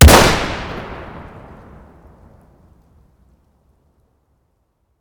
sv98_shoot.ogg